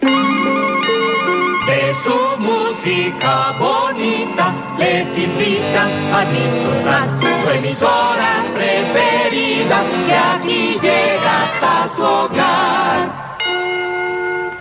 Indicatiu cantat